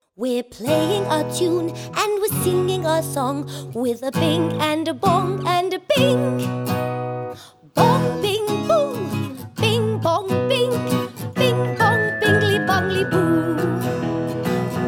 Extended No Kids Comedy/Novelty 1:27 Buy £1.50